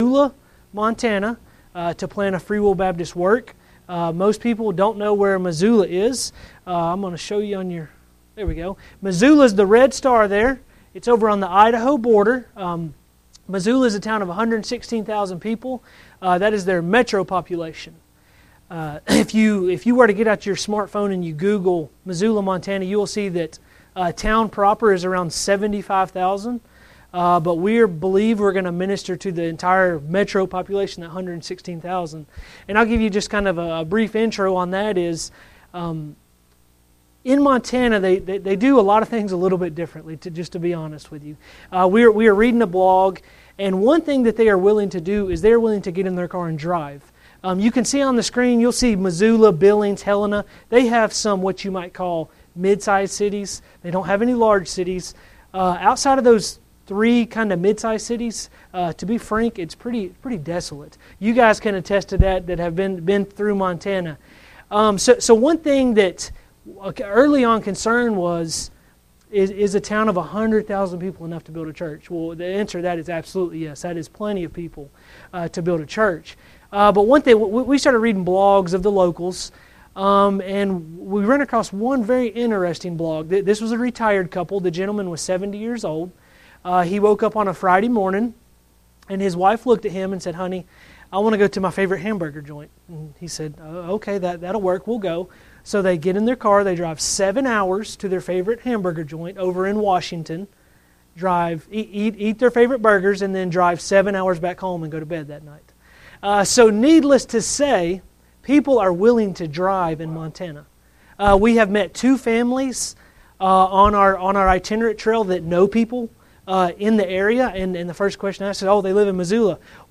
11:1,6 Service Type: Special Services Crazy Faith